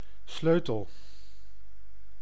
Ääntäminen
France: IPA: /kle/